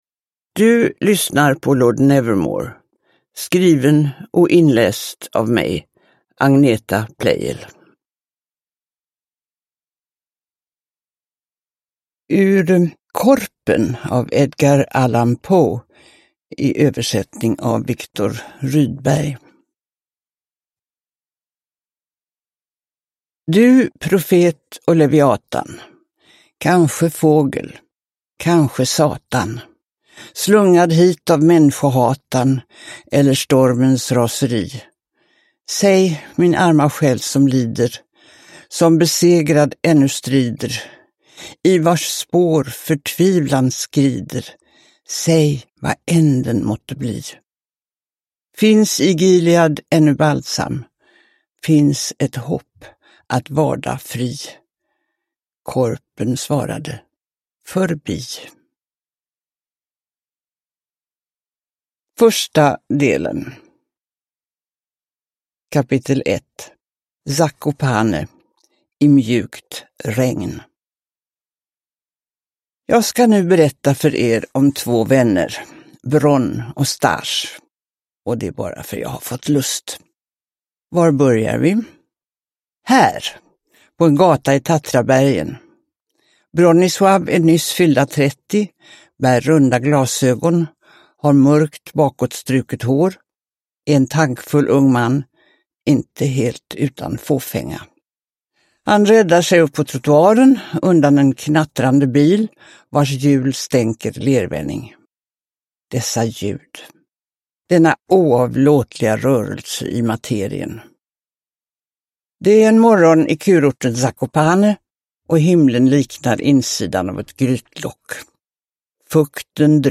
Lord Nevermore – Ljudbok – Laddas ner
Uppläsare: Agneta Pleijel